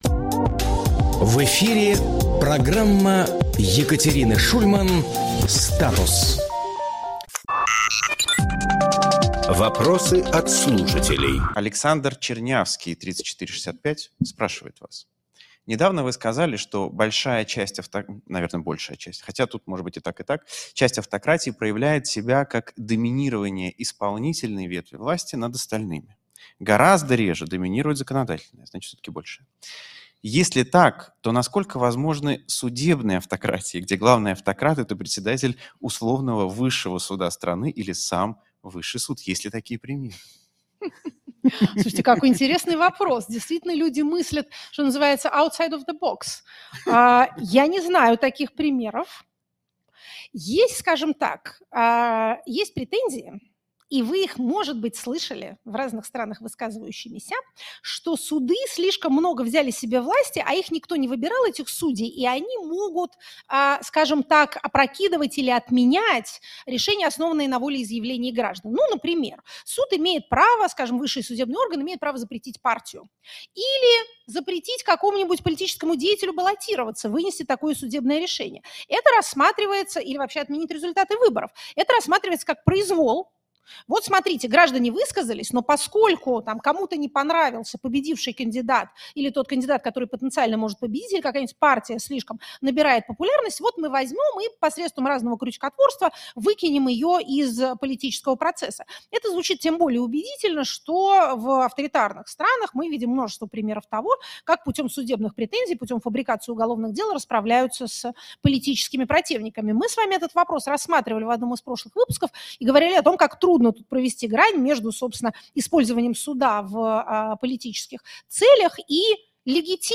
Фрагмент эфира от 22 апреля.